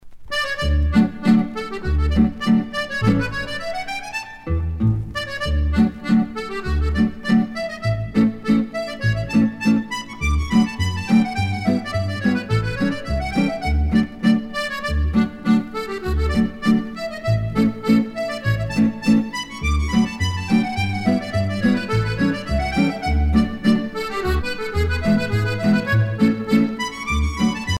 danse : hornpipe
Pièce musicale éditée